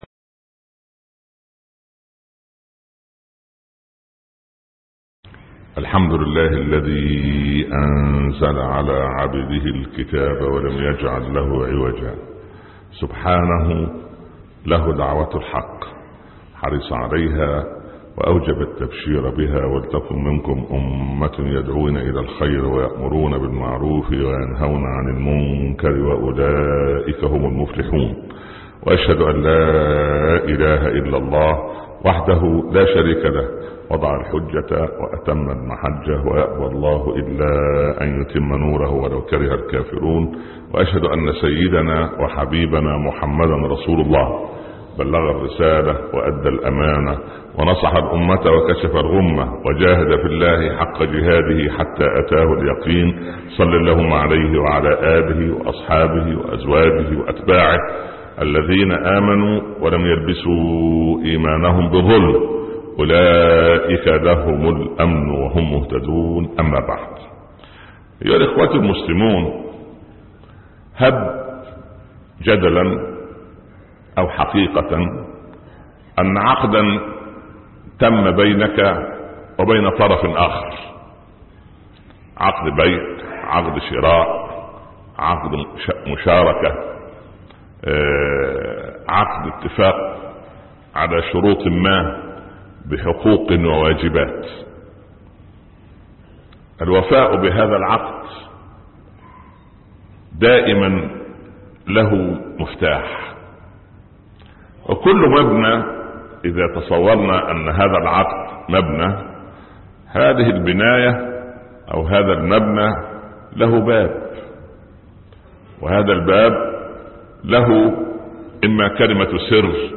مفاتيح الرضى ( خطب الجمعة) - الشيخ عمر بن عبدالكافي